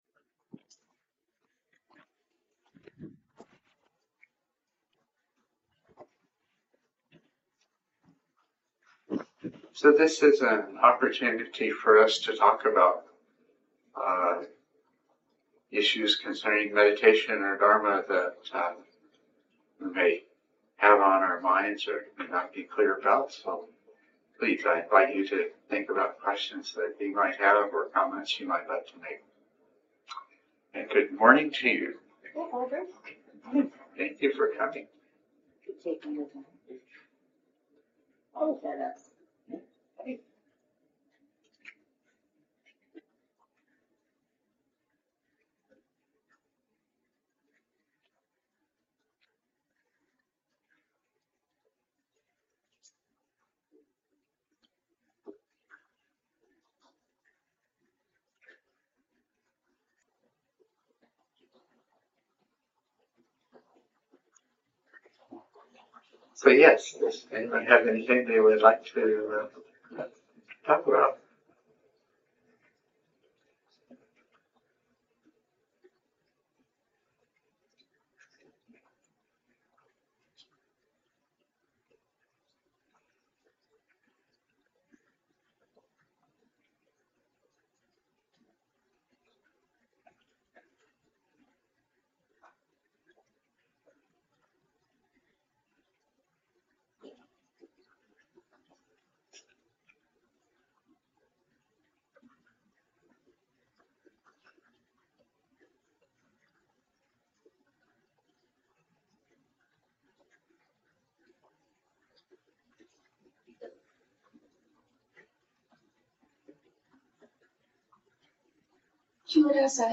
Edit talk Download audio (mp3) Download original audio Listen to original audio * Audio files are processed to reduce background noise, and provide (much) better compression.